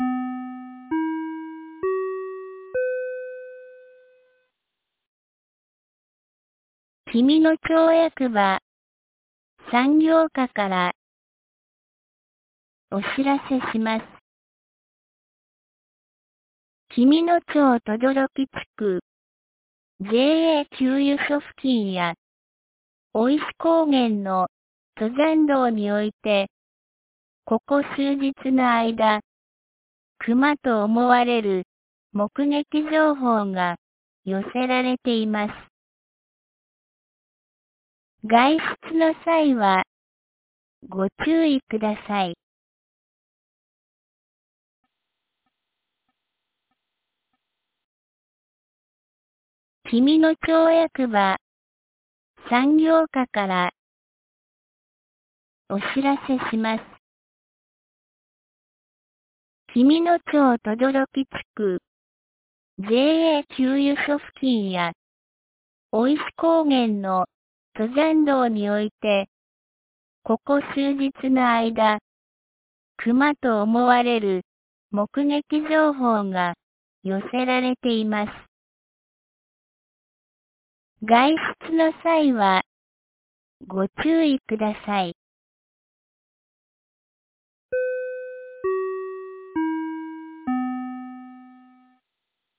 2021年05月18日 12時31分に、紀美野町より全地区へ放送がありました。